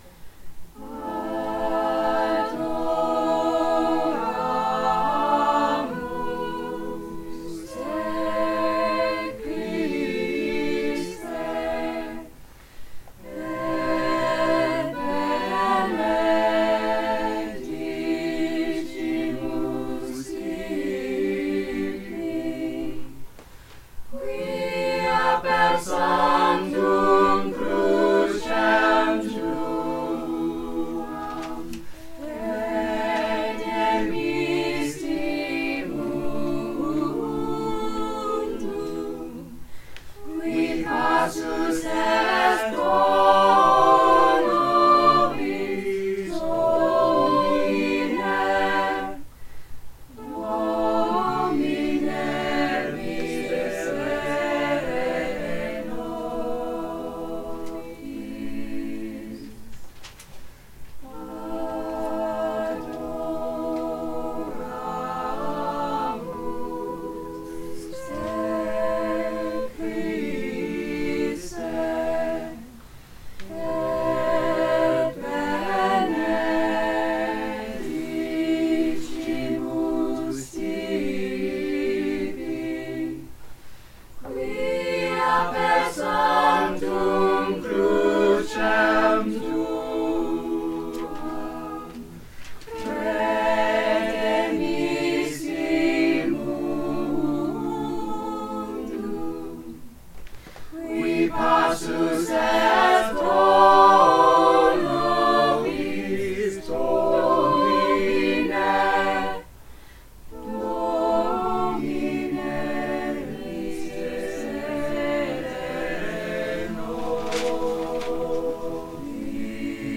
the students
Madrigals